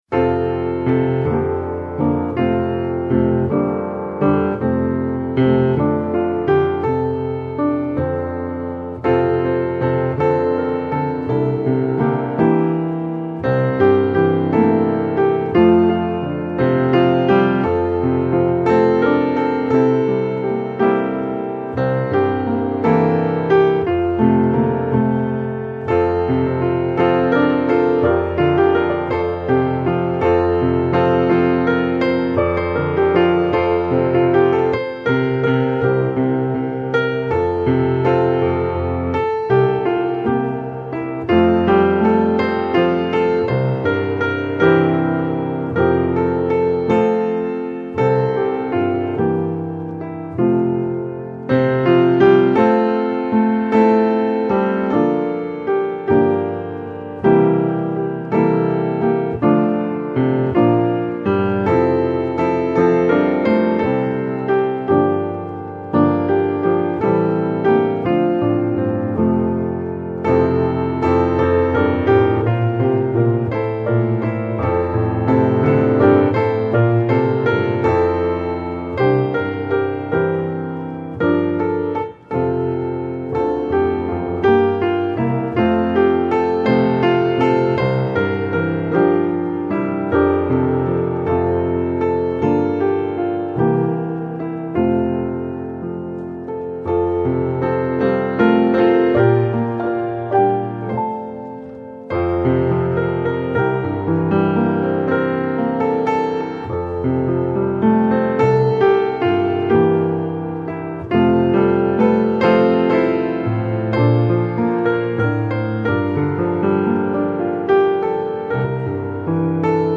Mainly Piano
Easy Listening